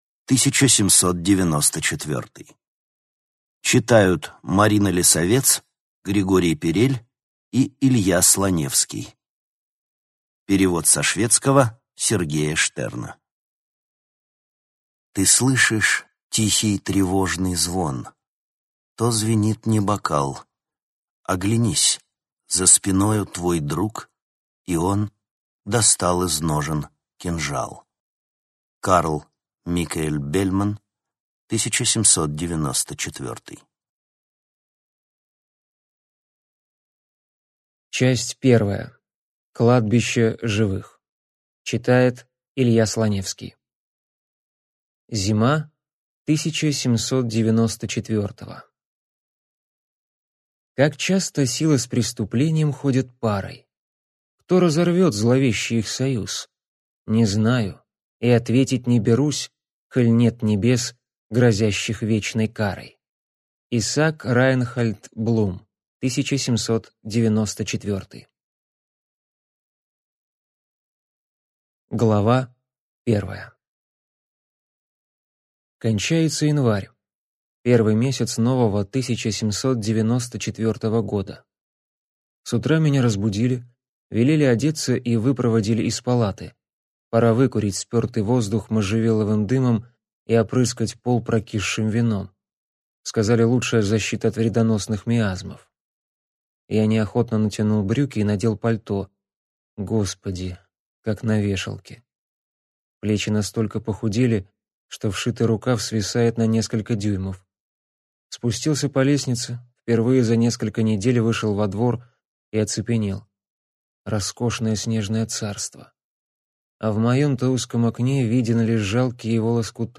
Аудиокнига 1794 | Библиотека аудиокниг